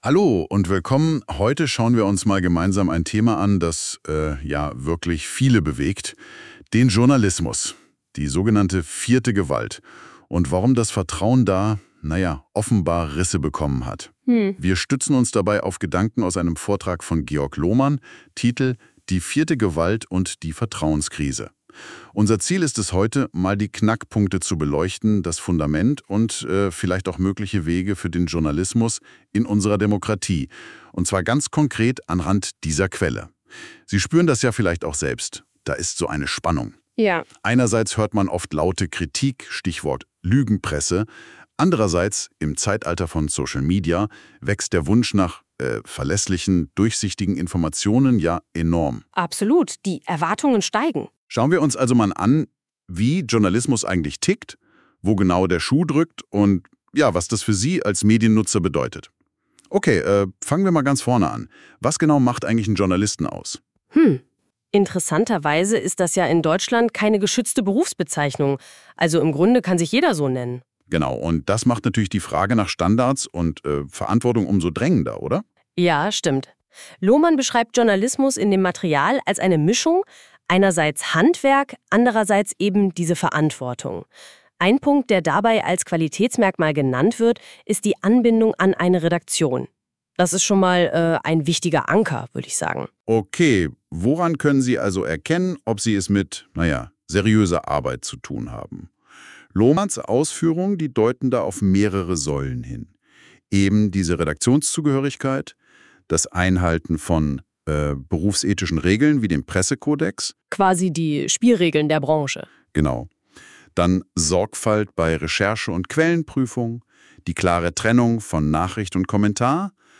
Unsere KI-Hosts diskutieren, wie Medien verlorenes Vertrauen zurückgewinnen können.